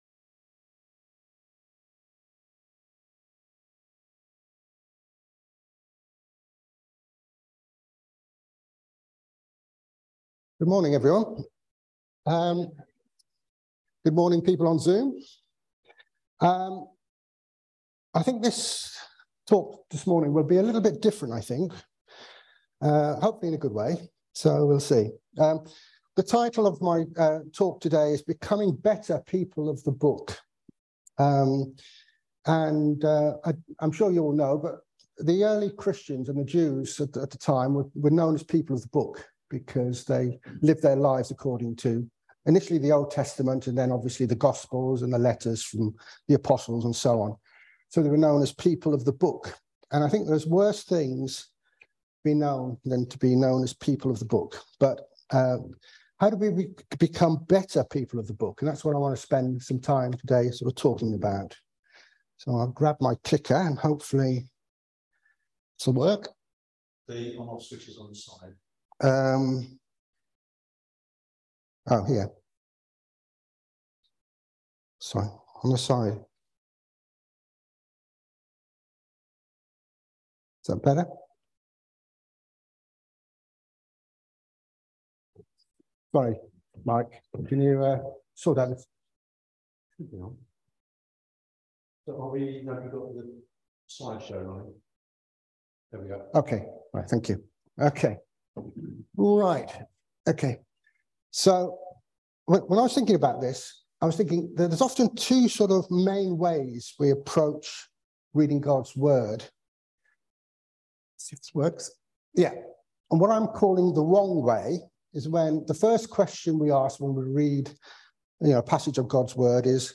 Service Type: Sunday Service Topics: Bible , Medittaion , Scripture , Study